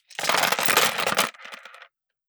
Gun Sold 005.wav